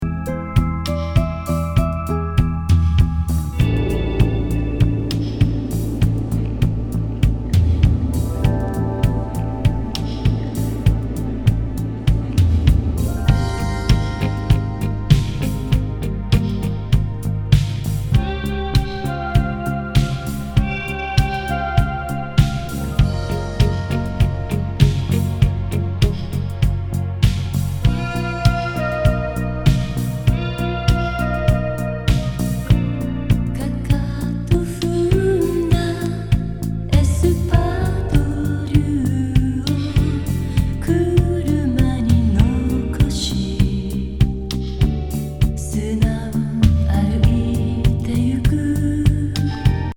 アダルト・メロウ・バレアリック